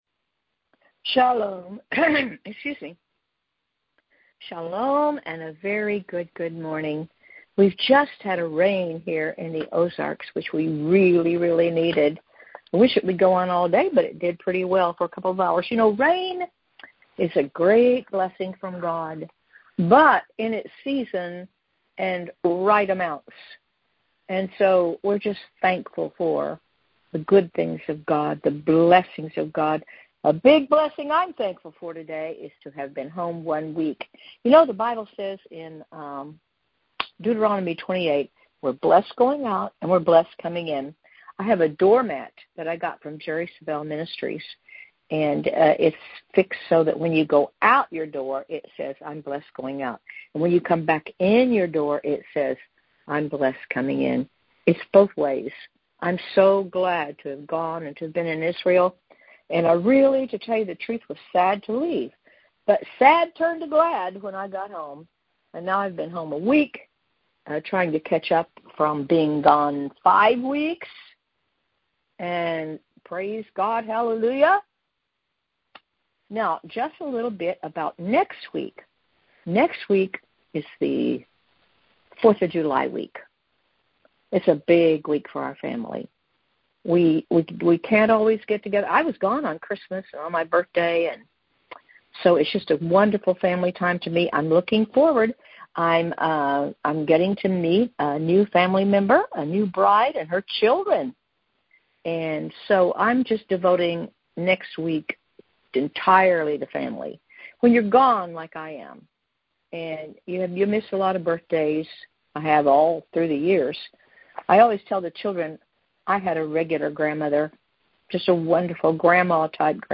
Prayer Call